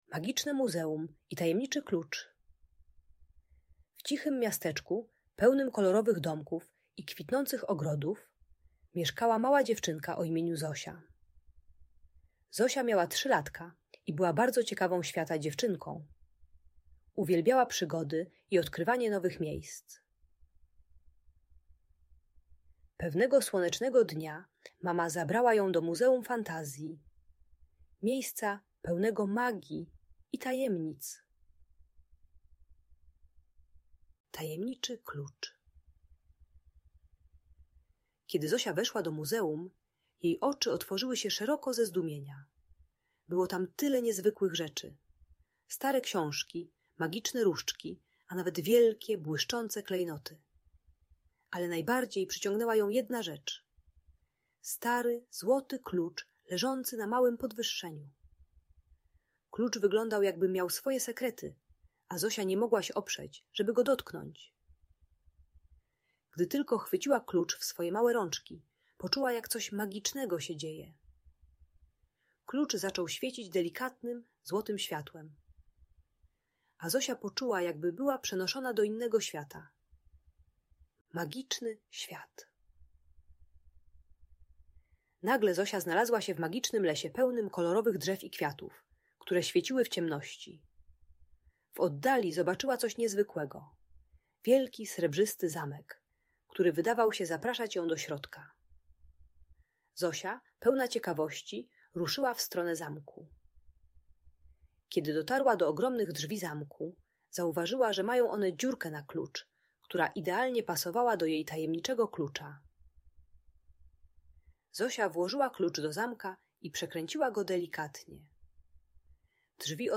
Magiczne Muzeum i Tajemniczy Klucz - Audiobajka